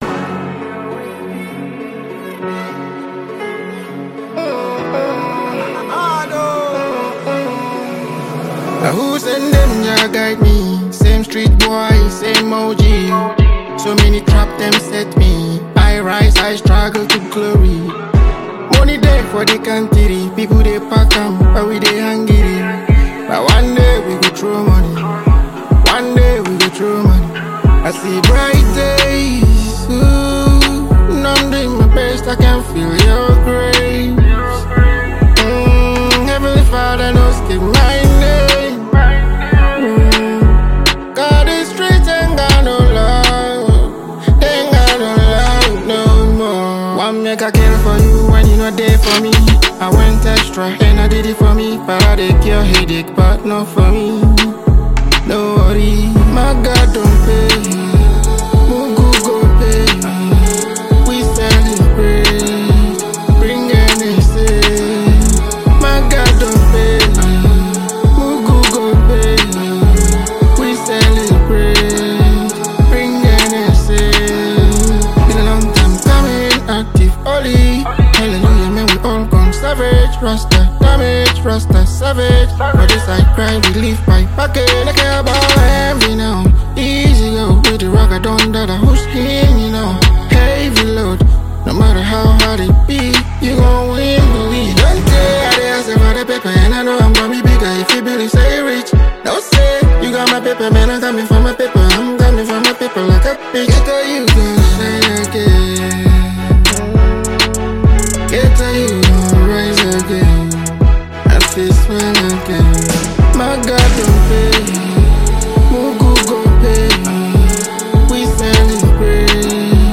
Genre: Highlife / Afrobeat